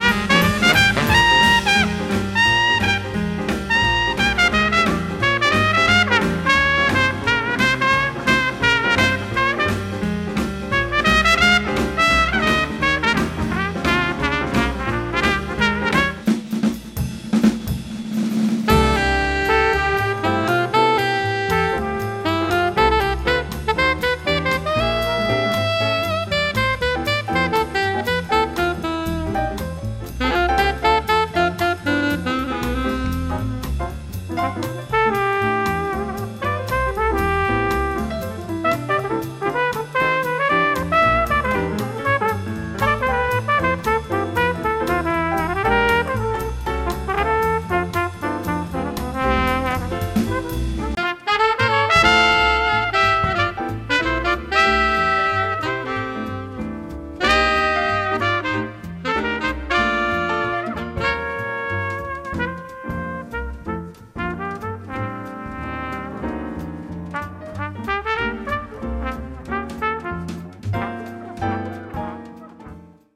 Genre: Swing.